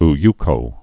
(-ykō)